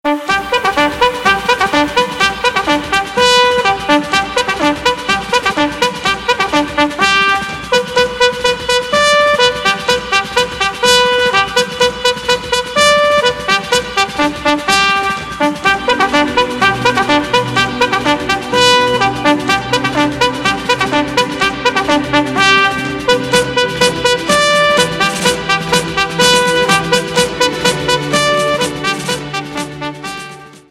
• Качество: 128, Stereo
Electronic
EDM
нарастающие
труба
бодрые
горн
Бодрое звучание трубы